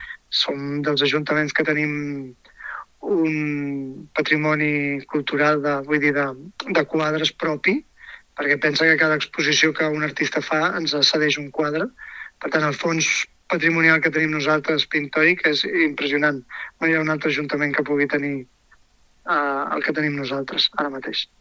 Amat afegeix que, gràcies al gran nombre d’exposicions que rep el Castell de Benedormiens, i en menor mesura també la Masia Bas, l’Ajuntament de Castell d’Aro, Platja d’Aro i S’Agaró és un dels consistoris amb un fons patrimonial més extens i ric.